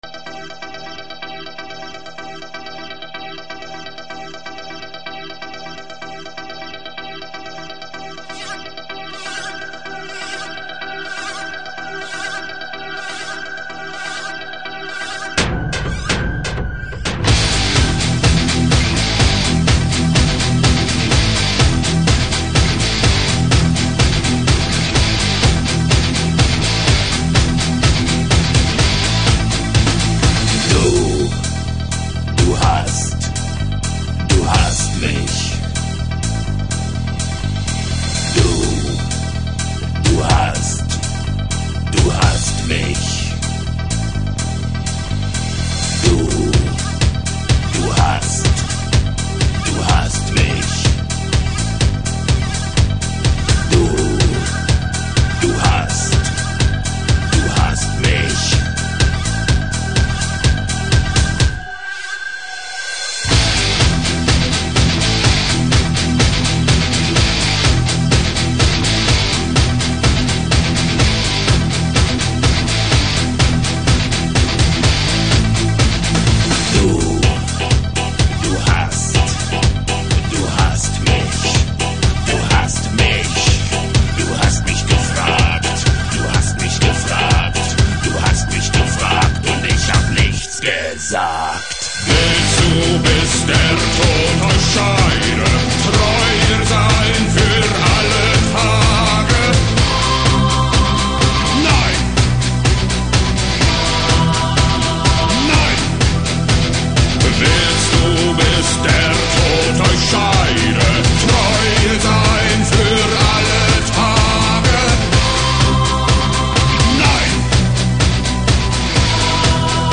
Немецкая музыка - рок!